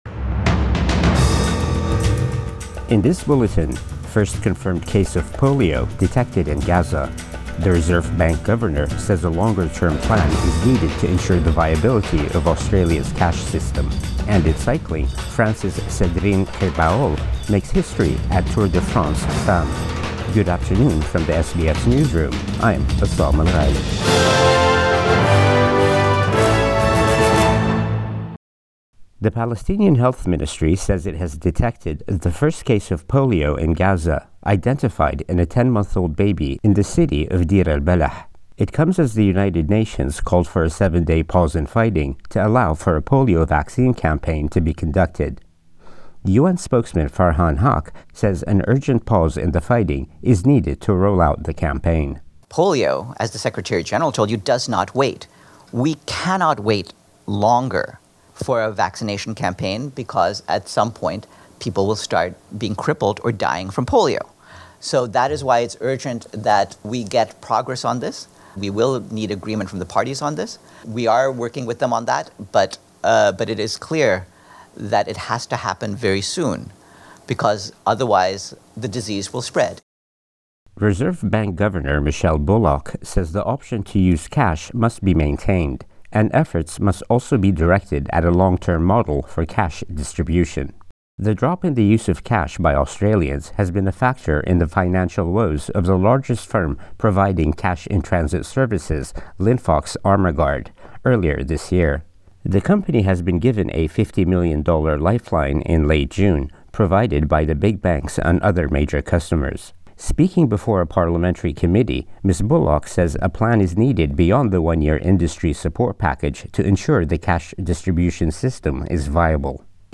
Midday News Bulletin 17 August 2024